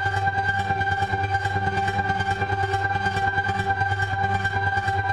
Index of /musicradar/dystopian-drone-samples/Tempo Loops/140bpm
DD_TempoDroneB_140-G.wav